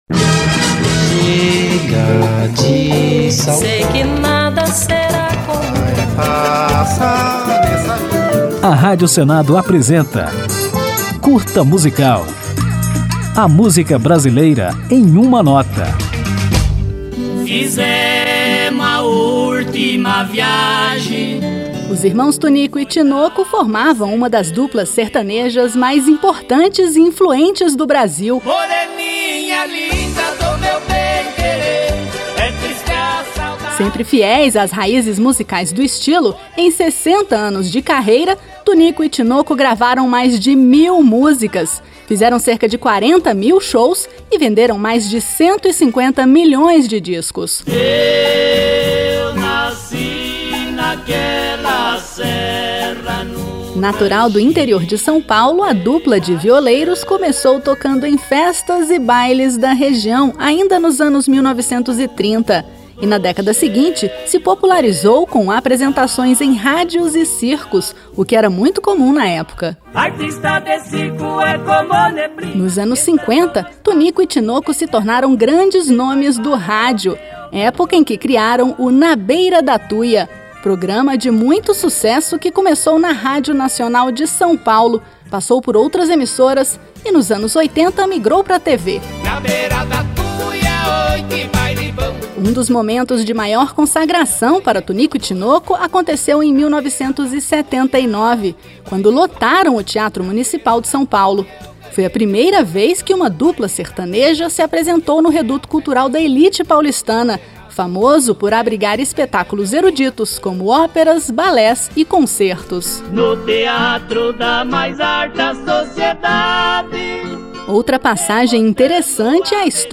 Ao final, ouviremos o maior clássico de Tonico & Tinoco, a música Chico Mineiro.